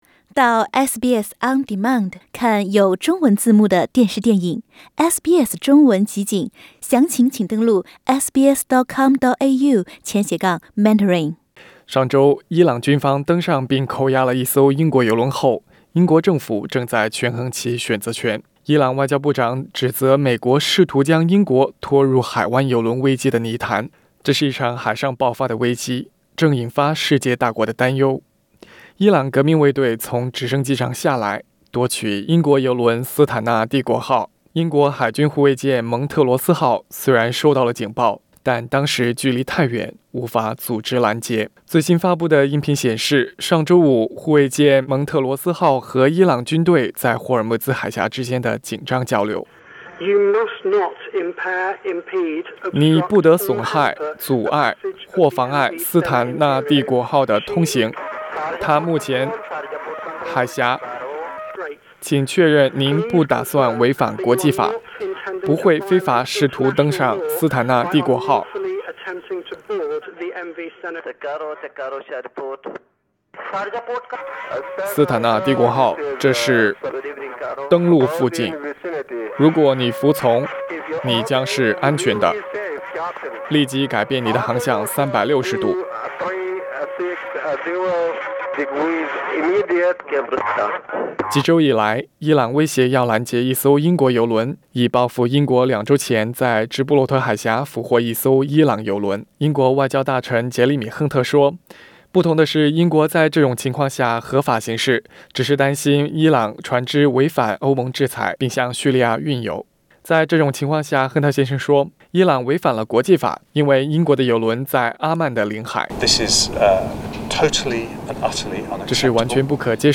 “蒙特罗斯号”无线电音频曝光 英国与伊朗海上交涉紧张